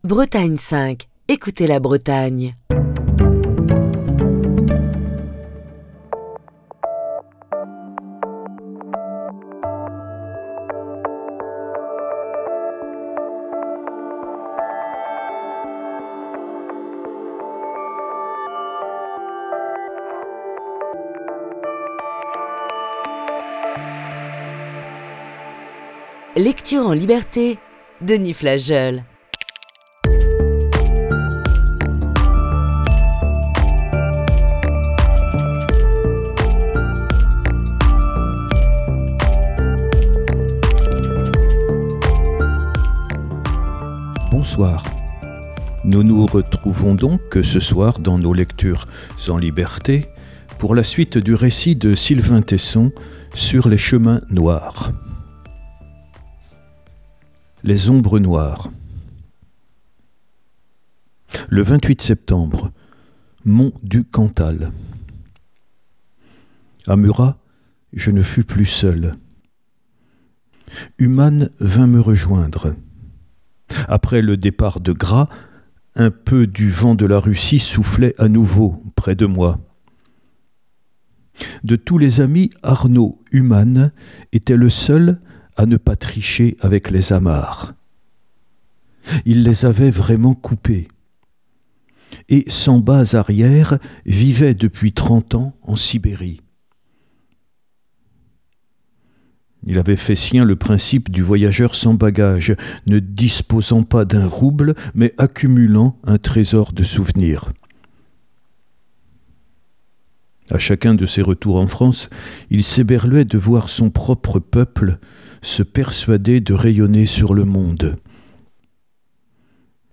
Émission du 14 octobre 2021.